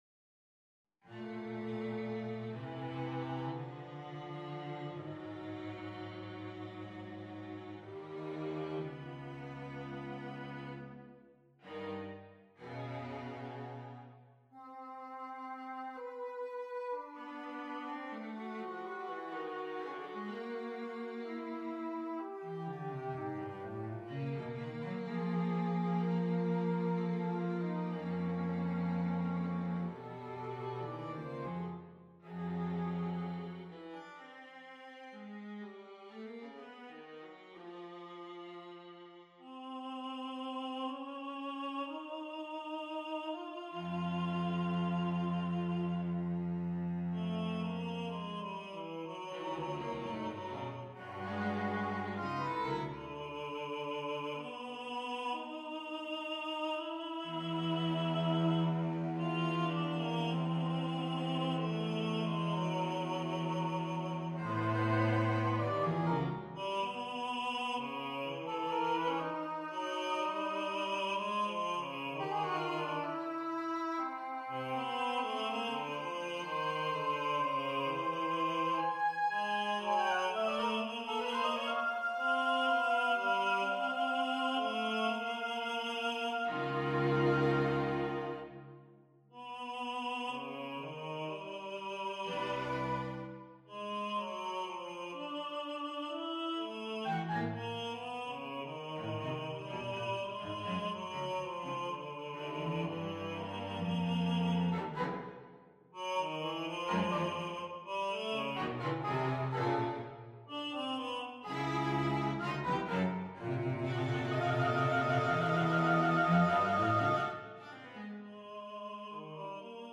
on a purpose-selected tone row
C-B-D-Gb-E-G-Ab-Bb-A-F-Eb-Db